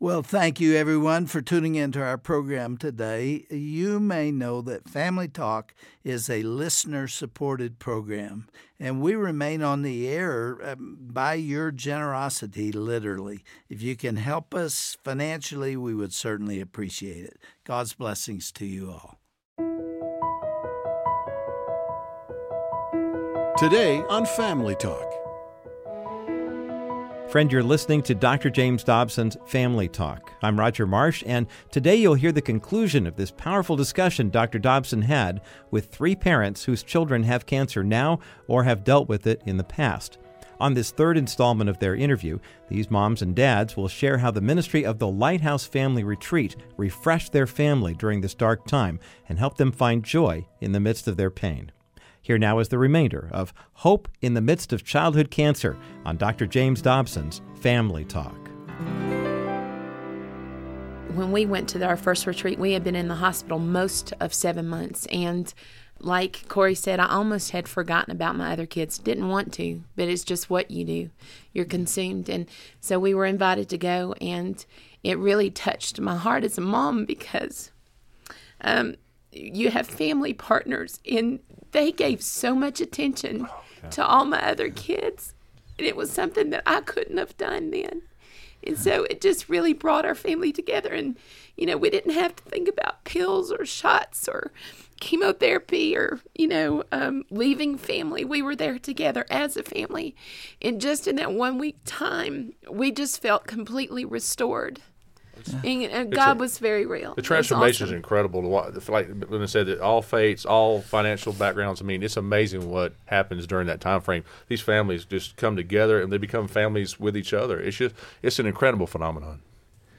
Listen to this encouraging and uplifting broadcast of Dr. James Dobsons Family Talk.